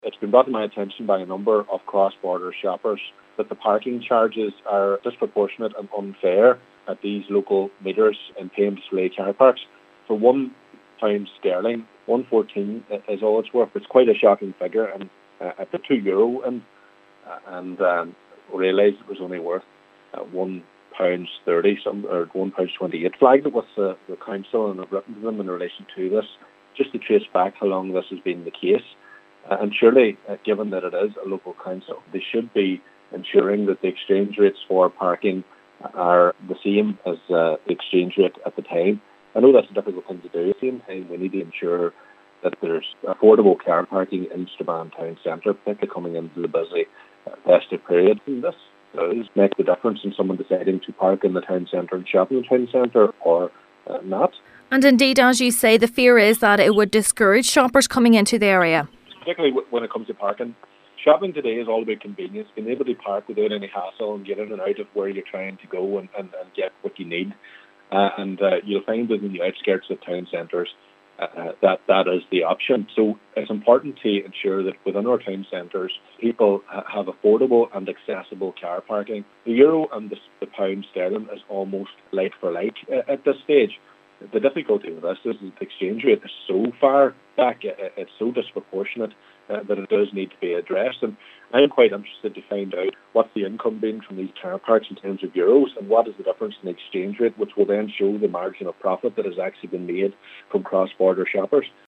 West Tyrone MLA Daniel McCrossan fears the excessive charges will discourage people from parking in the town centre: